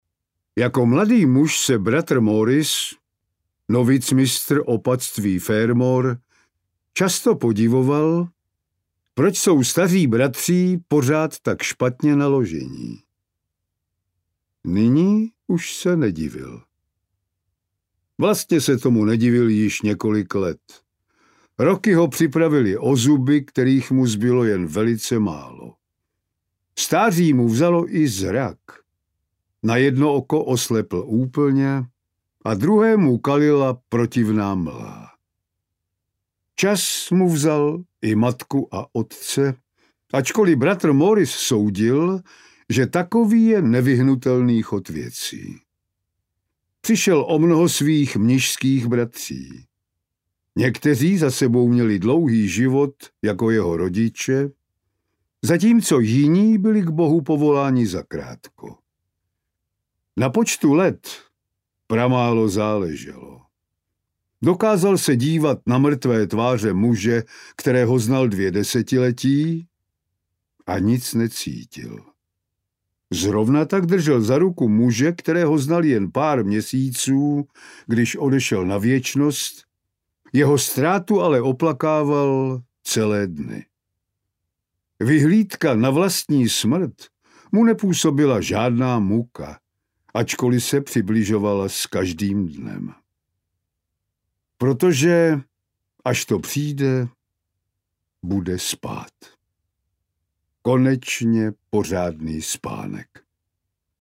Vraždy v klášteře audiokniha
Ukázka z knihy
vrazdy-v-klastere-audiokniha